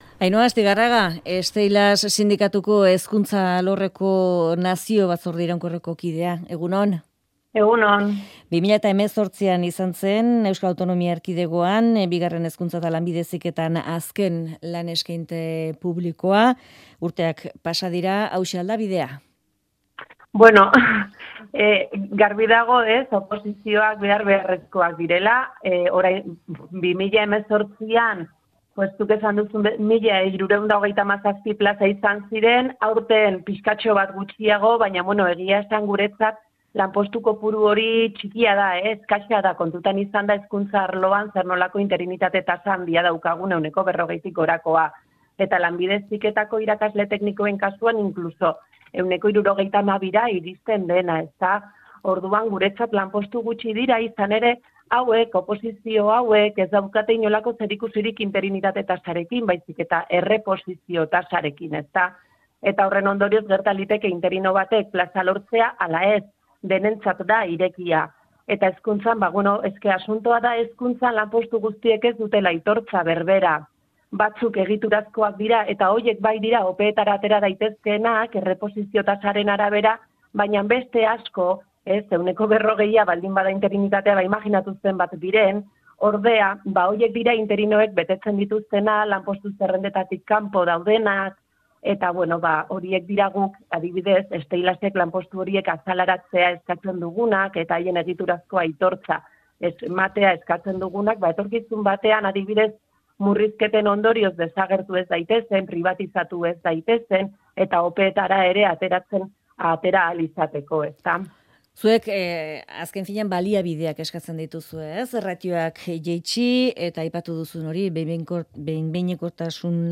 Euskadi Irratiko Goiz Kronikan hezkuntzako lanpostu guztien aitortza eskatu du.